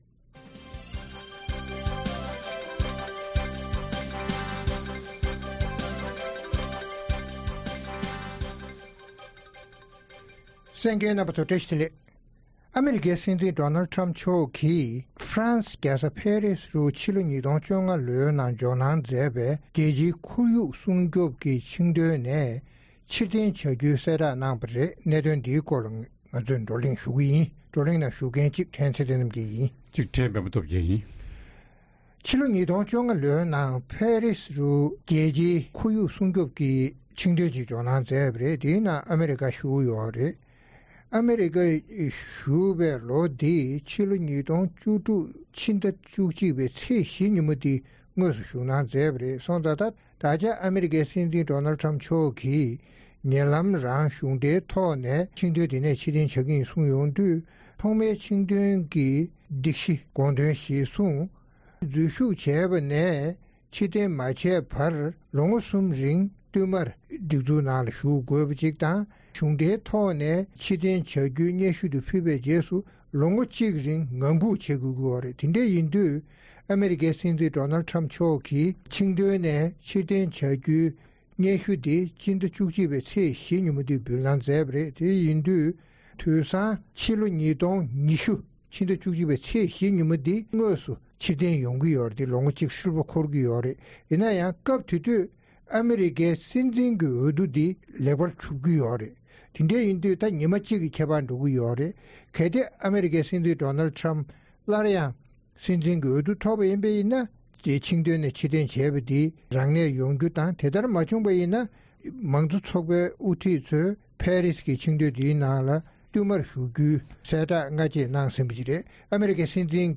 རྩོམ་སྒྲིག་པའི་གླེང་སྟེགས་ཞེས་པའི་ལེ་ཙན་ནང་། ཨ་རིའི་སྲིད་འཛིན་Donald Trump མཆོག་གིས་དེ་སྔོན་འཛུལ་ཞུགས་གནང་པའི་Paris Accord ཞེས་འཛམ་གླིང་གནམ་གཤིས་འགྱུར་ལྡོག་འགོག་ཐབས་ཀྱི་གྲོས་མཐུན་ནས་གཞུང་འབྲེལ་ཕྱིར་འཐེན་གནང་བའི་གསལ་བསྒྲགས་གནང་བ་སོགས་ཀྱི་སྐོར་རྩོམ་སྒྲིག་འགན་འཛིན་རྣམ་པས་བགྲོ་གླེང་གནང་བ་གསན་རོགས་གནང་།